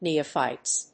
/ˈniʌˌfaɪts(米国英語), ˈni:ʌˌfaɪts(英国英語)/